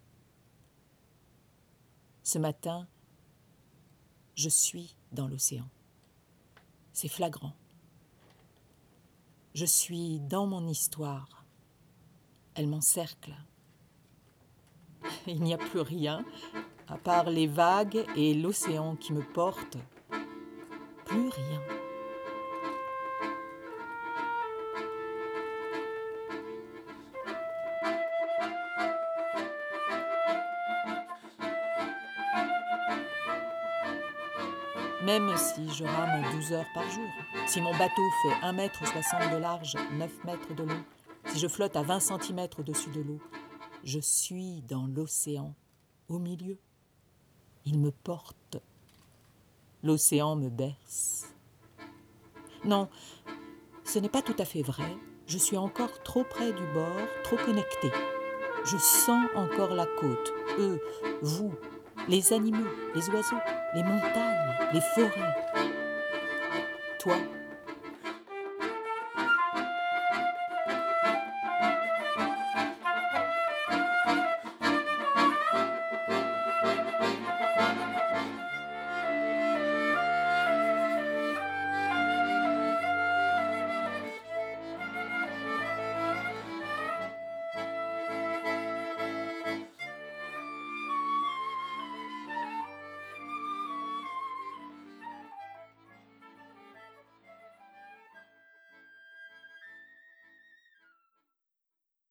Accordéon
Flûte traversière
Ballotés au dessus de l'abysse, ils s'amusent, légers, drôles, poétiques.